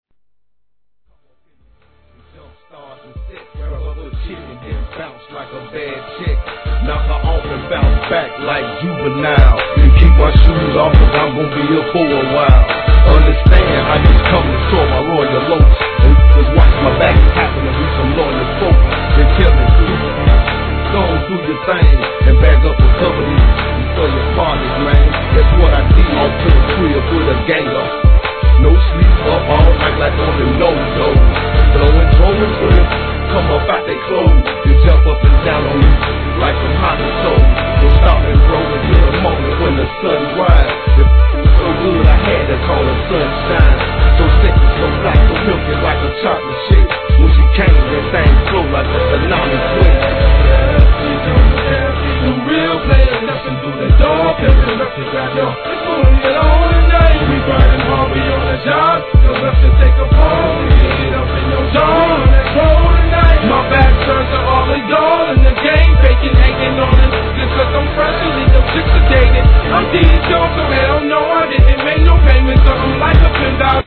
G-RAP/WEST COAST/SOUTH
イントロから不穏なシンセ響き渡る超ダークな雰囲気だしまくり。